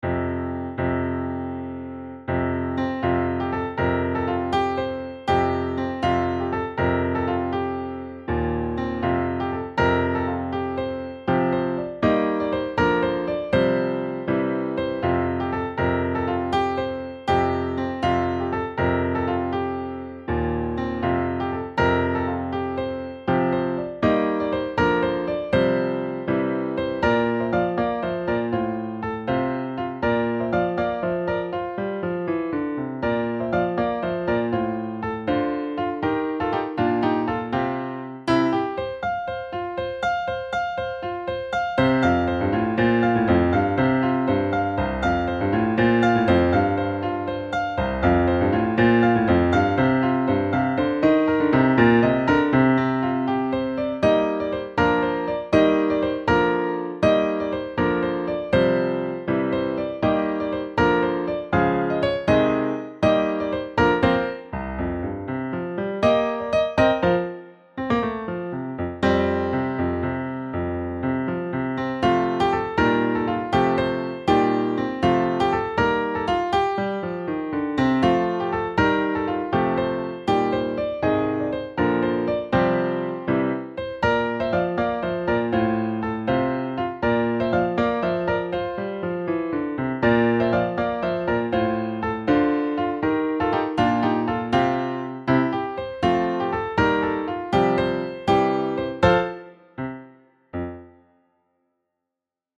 for the Intermediate Pianist
• Piano